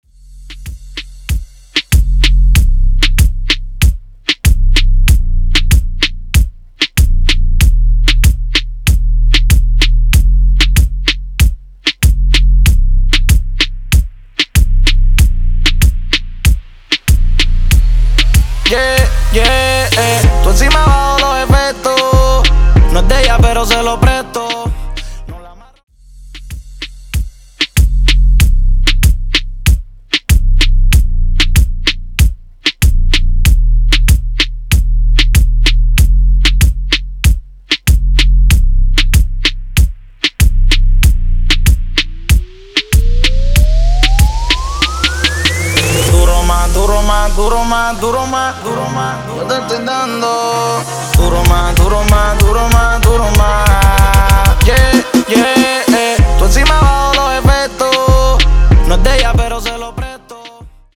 Coro Dirty, Pre Coro Hype Dirty